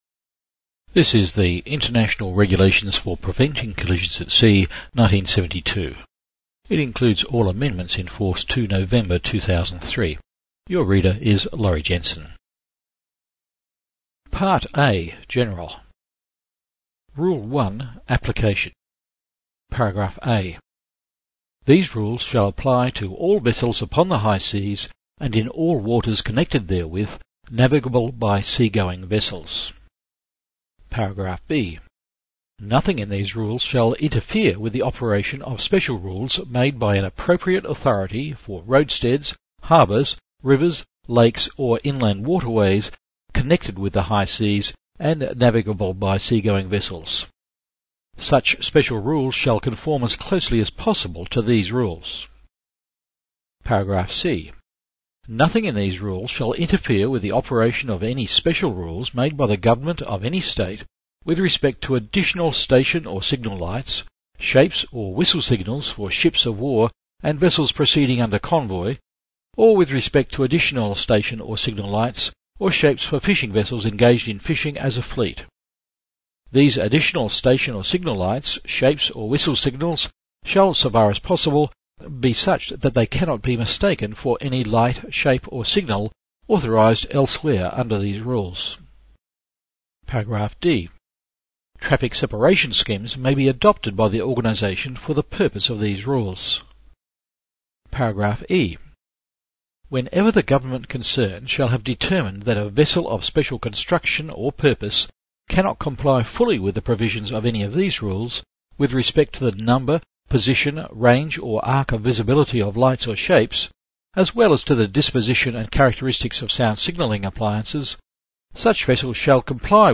MP3 Audio Narration of COLREGS Rules 1-19